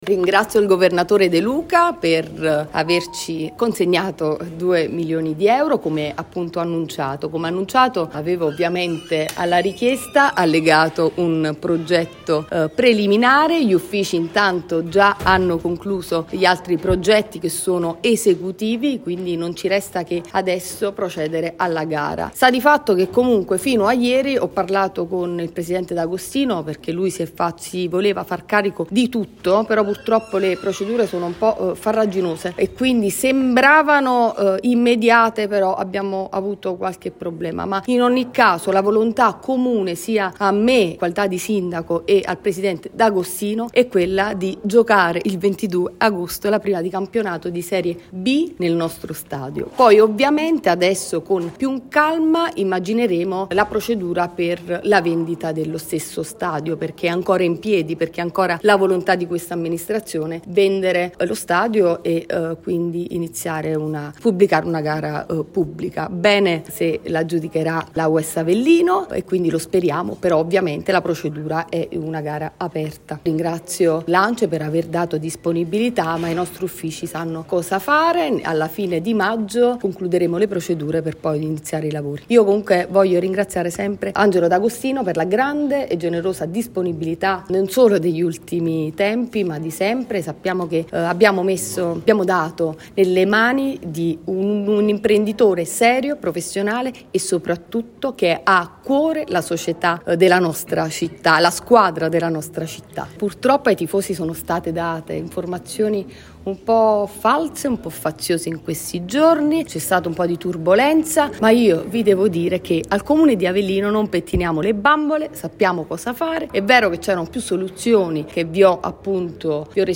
A margine della conferenza stampa odierna, la Sindaca Laura Nargi è tornata a parlare della soluzione trovata per avviare i lavori di adeguamento dello stadio “Partenio-Lombardi”. Dai fondi stanziati dalla Regione Campania al tentativo del presidente D’Agostino di venire incontro all’amministrazione comunale. L’obiettivo è giocare in casa dal prossimo 22 agosto.